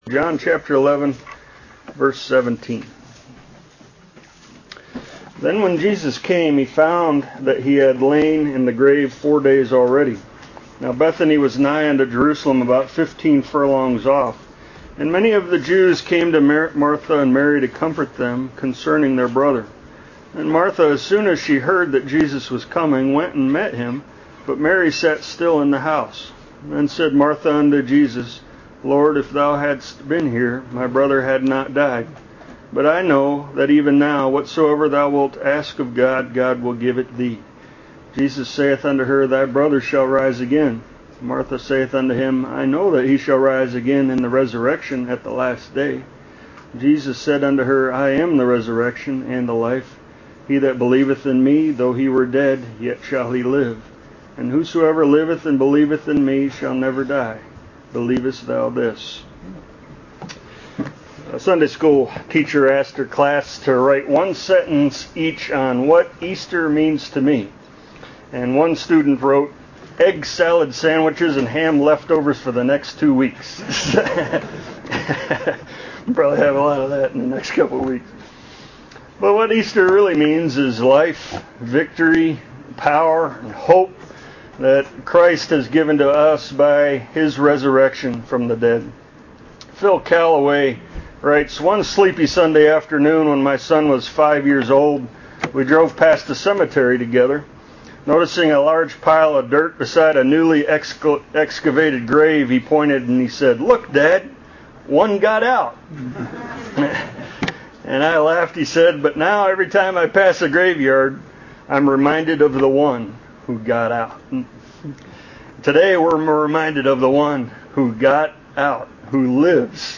You're listening to Lesson 12 from the sermon series "The Miracles of Christ"